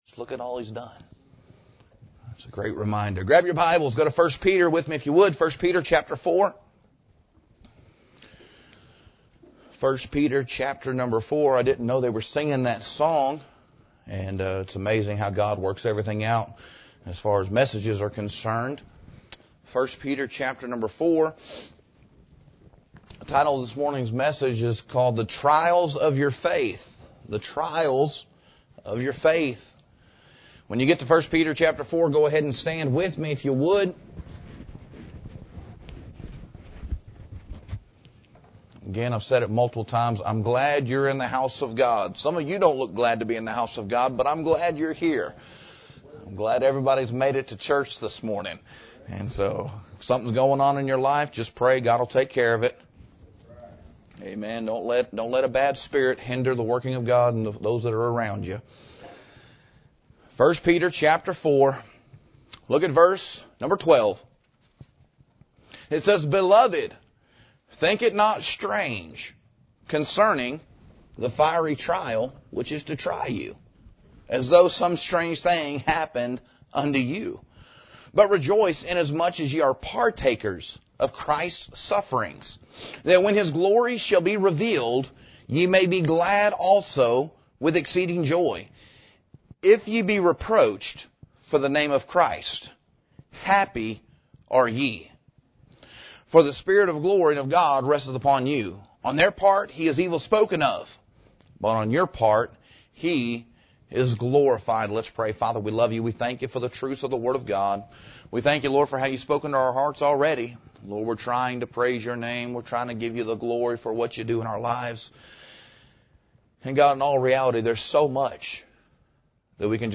The title of this morning’s message is called the trials of your faith.